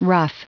Prononciation du mot ruff en anglais (fichier audio)
Prononciation du mot : ruff